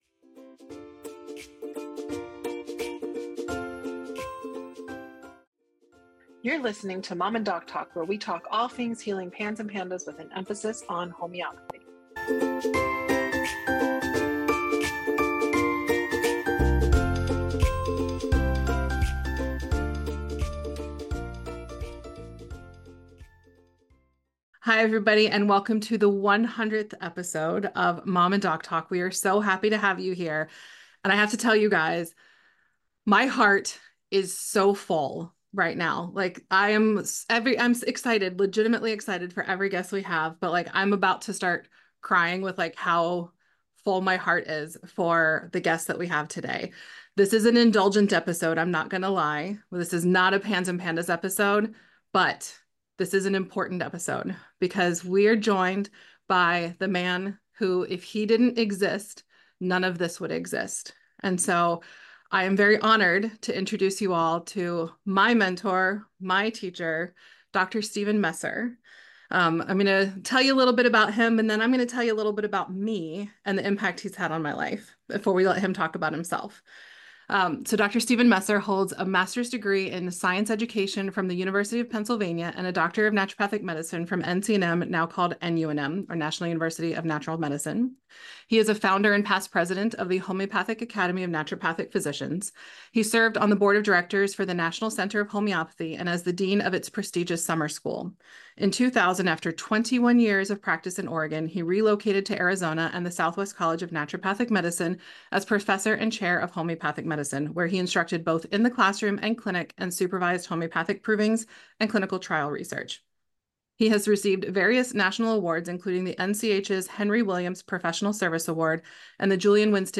This conversation is more than just meaningful—it’s foundational.